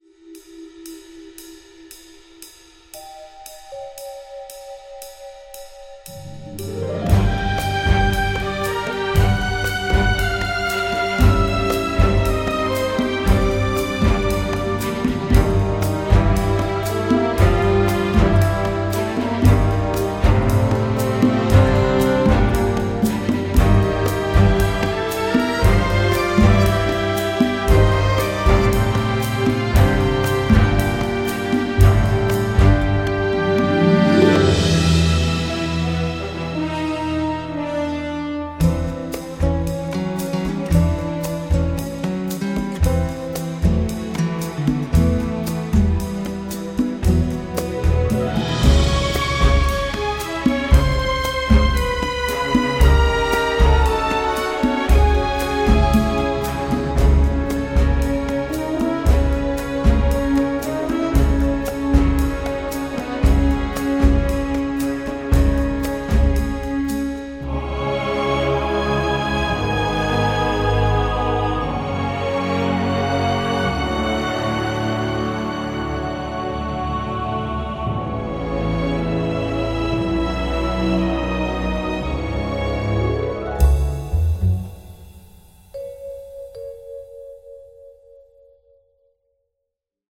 propulsive orchestral score
drenched in delightful old school film-noir vibes